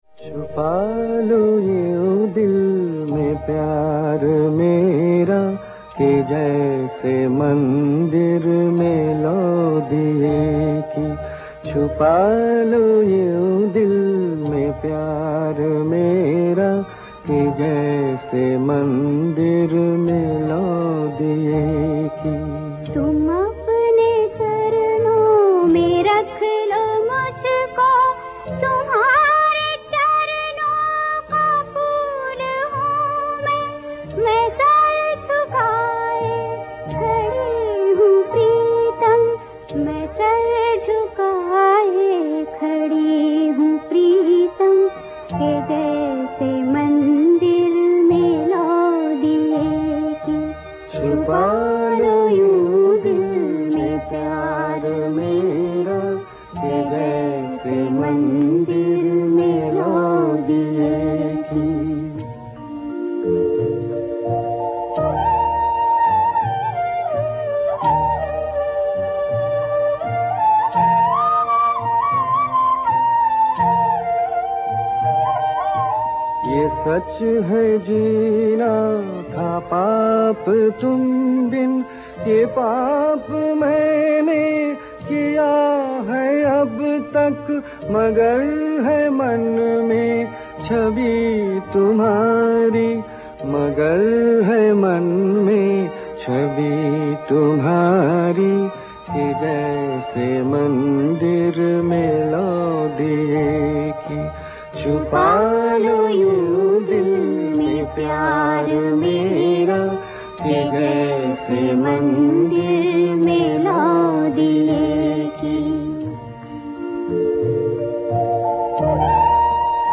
A haunting composition